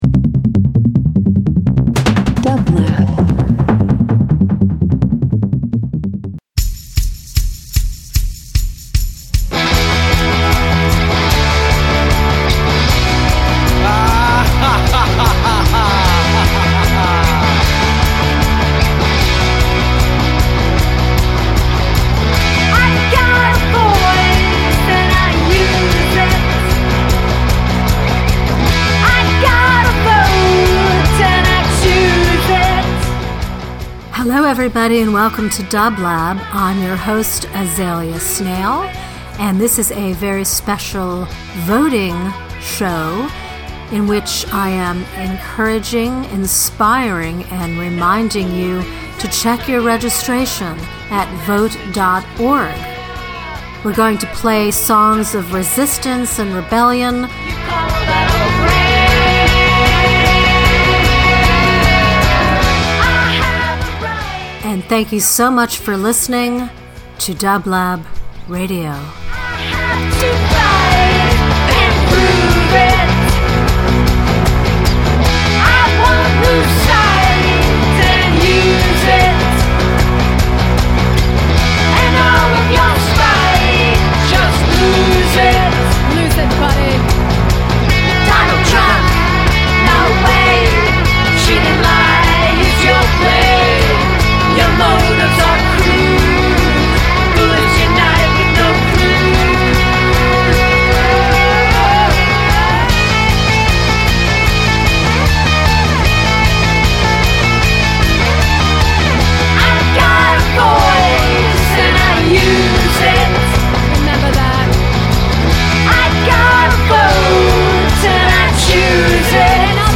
radio show
Psych Rock